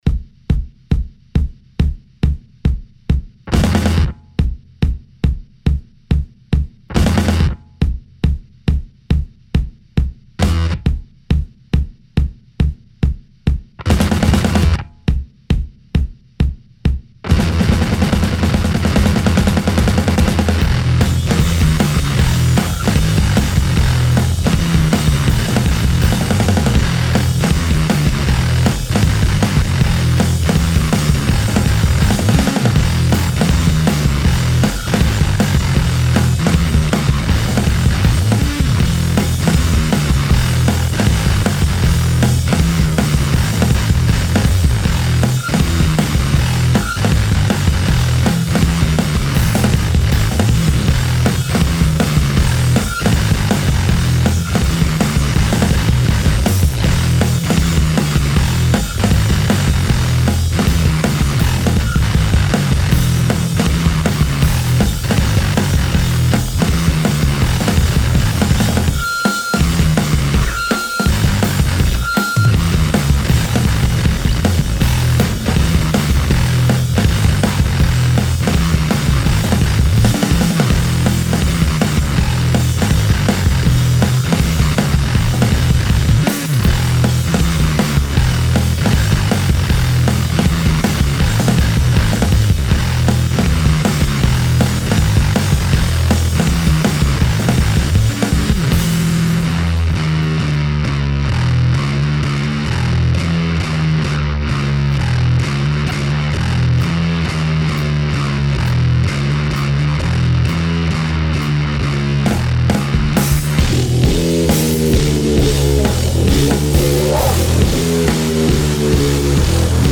Leeds based duo